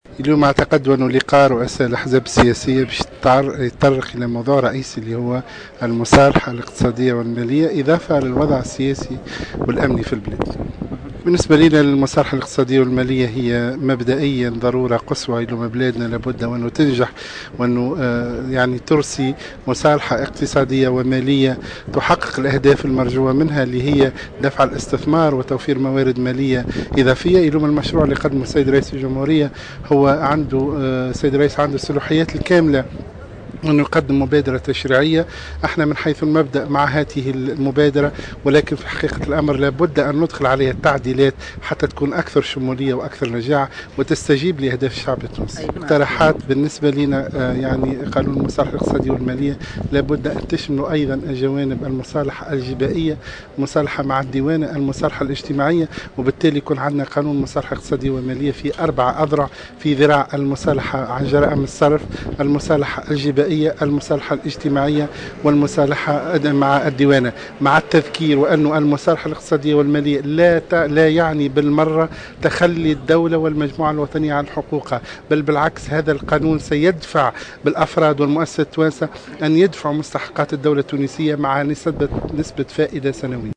أكد الناطق الرسمي لحزب الإتحاد الوطني الحر محسن حسن، في تصريح لمراسلة الجوهرة أف أم أن لقاء رؤساء أحزاب الائتلاف الحاكم الذي يعقد اليوم الثلاثاء في مقر حركة نداء تونس، سيتطرق بالخصوص إلى موضوع المصالحة الاقتصادية إضافة إلى الوضع السياسي والاقتصادي الحالي.